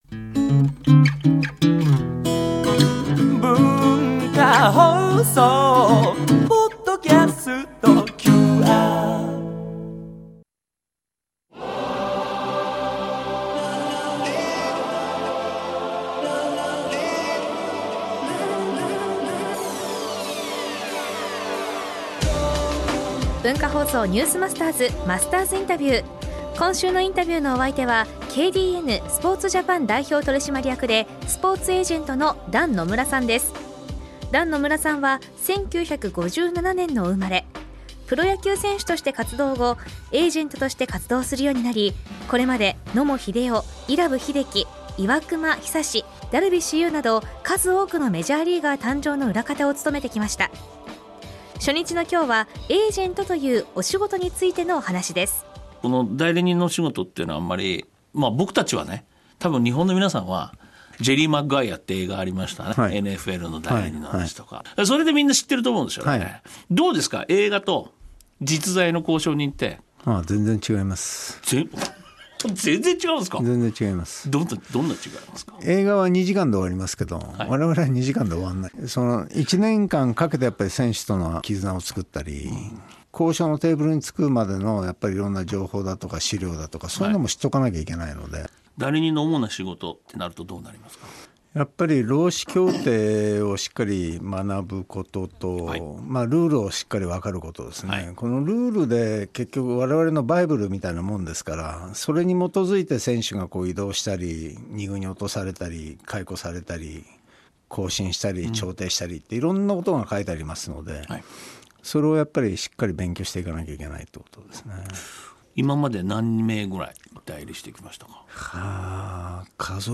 今週のインタビューのお相手はKDNスポーツジャパン代表取締役でスポーツエージェントの団野村さん。
（月）～（金）AM7：00～9：00　文化放送にて生放送！